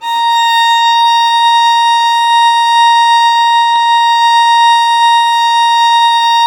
Index of /90_sSampleCDs/Roland - String Master Series/STR_Violin 4 nv/STR_Vln4 no vib
STR VLN BO0I.wav